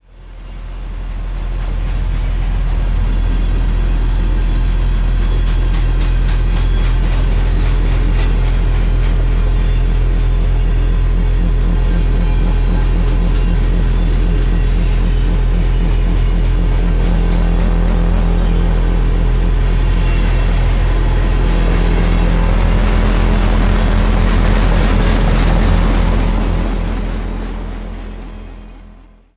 Genre : Blues.